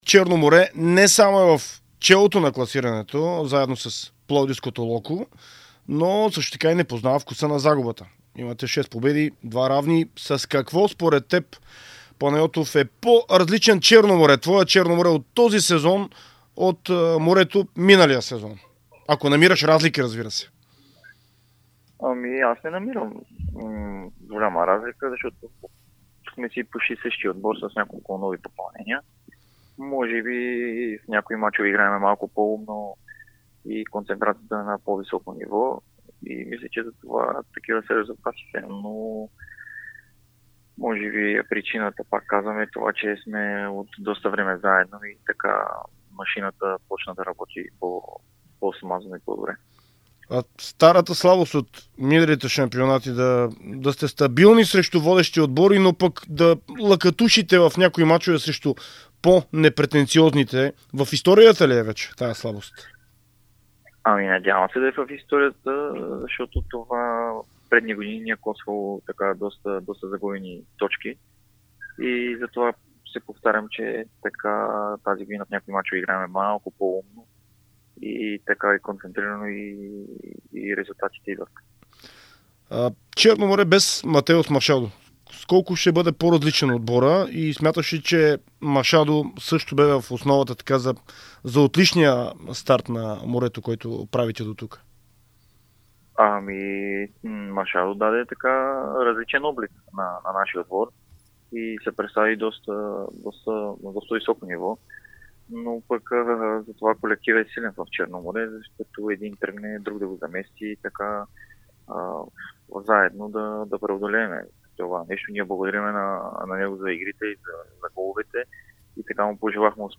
говори ексклузивно пред „Дарик“ радио и dsport за впечатленията си до този момент през сезона в efbet Лига и очакванията му за предстоящите срещи в шампионата.